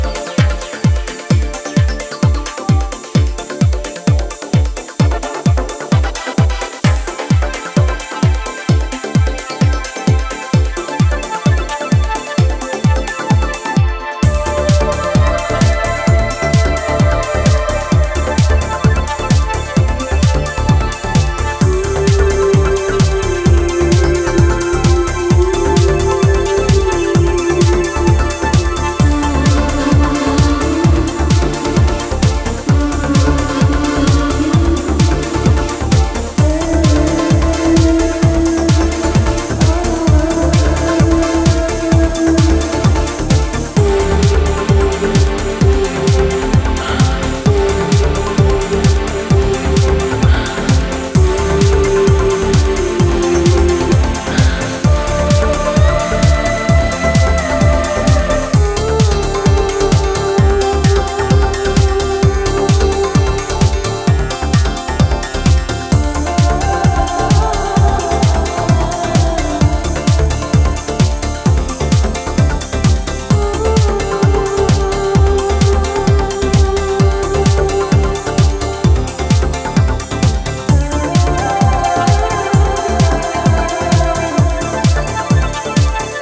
a sensual vocal track